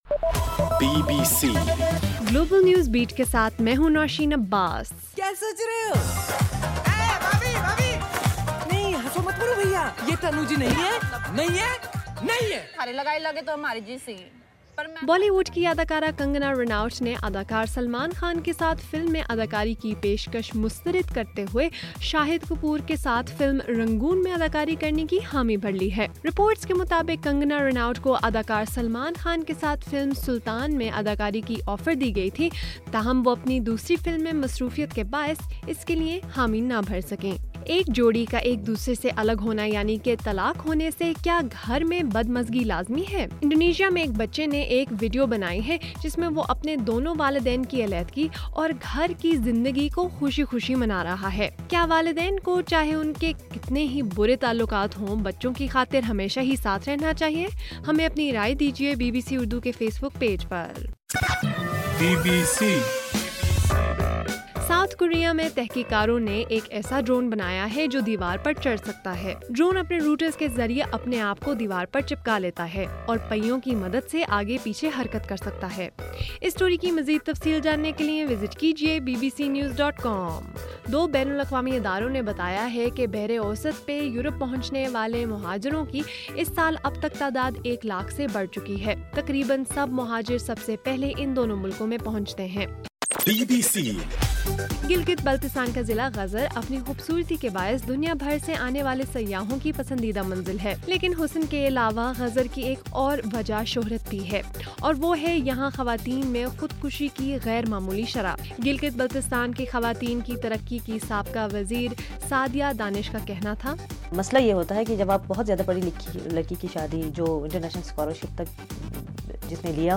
جون 9: رات 9 بجے کا گلوبل نیوز بیٹ بُلیٹن